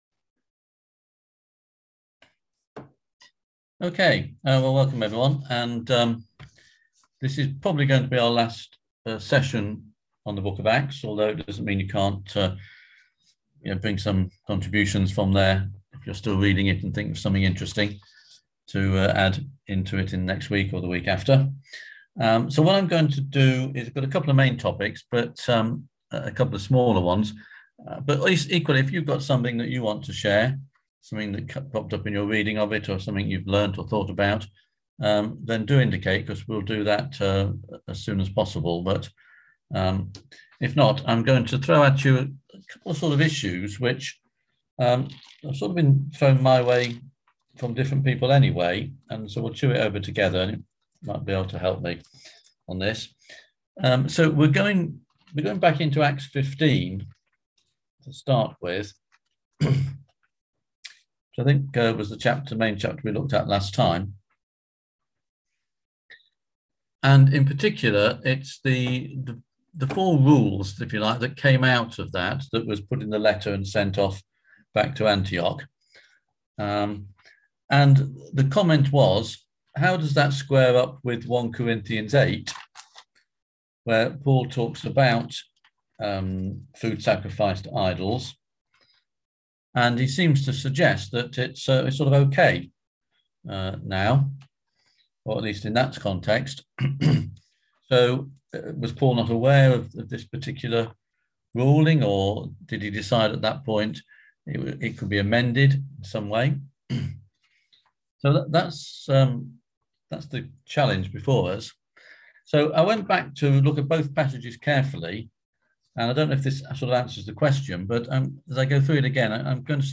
On October 21st at 7pm – 8:30pm on ZOOM